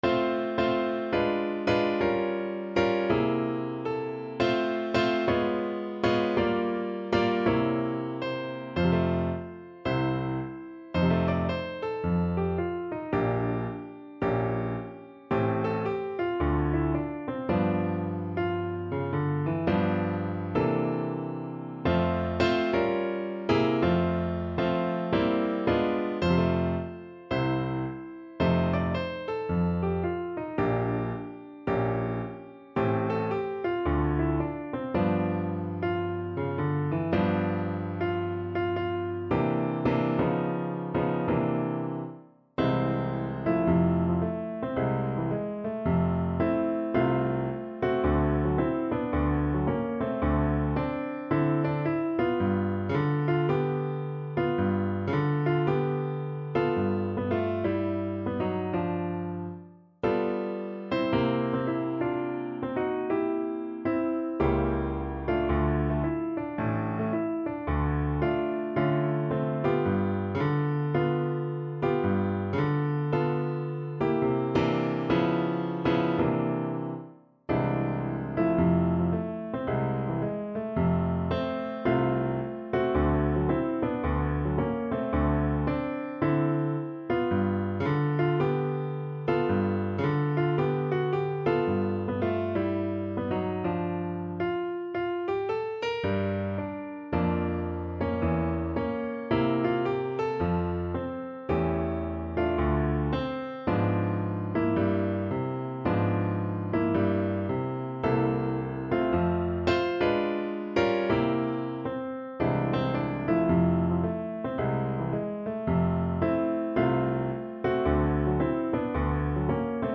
~ = 110 Moderate swing